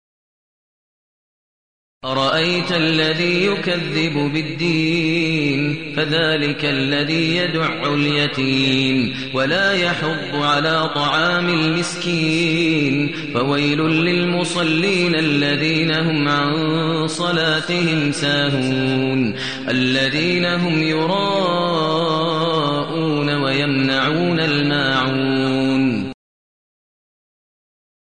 المكان: المسجد النبوي الشيخ: فضيلة الشيخ ماهر المعيقلي فضيلة الشيخ ماهر المعيقلي الماعون